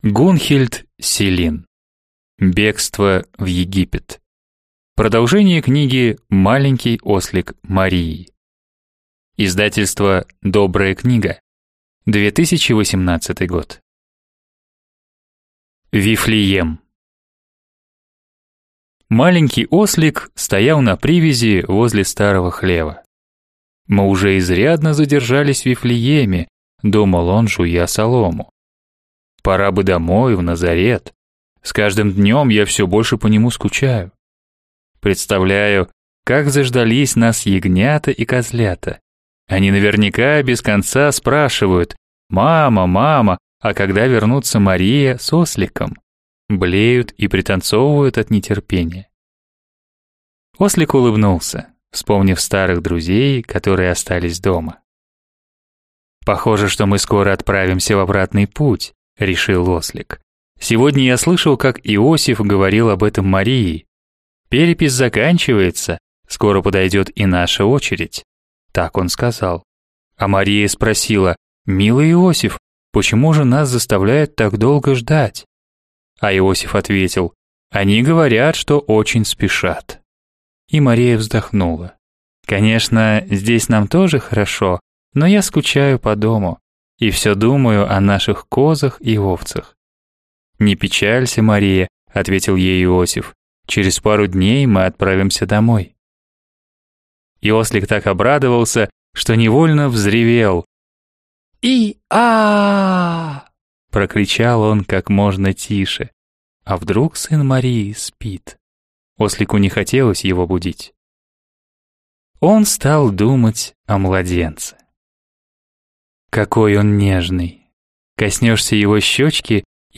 Аудиокнига Бегство в Египет | Библиотека аудиокниг